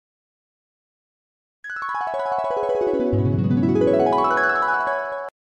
Dreaming Harp Sound effect sound effects free download